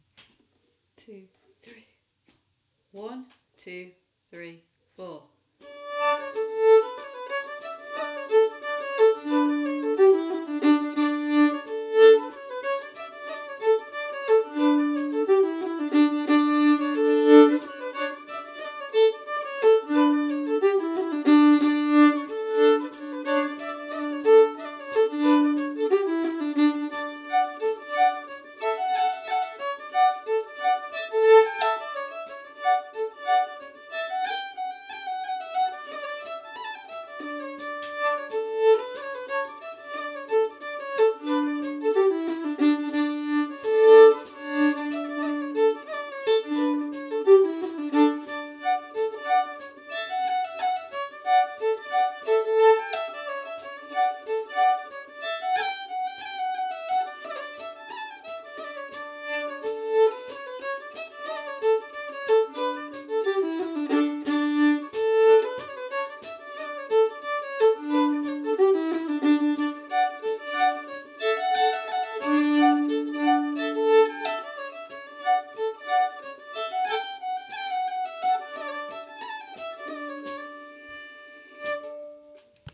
Whole Tune